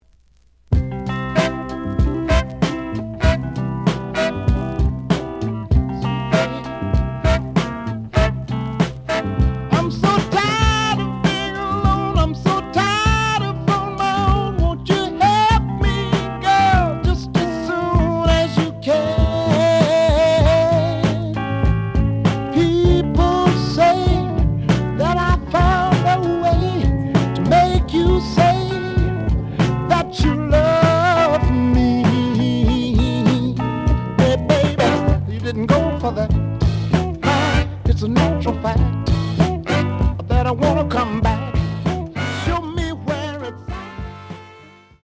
The audio sample is recorded from the actual item.
●Genre: Soul, 70's Soul
Slight noise on A side.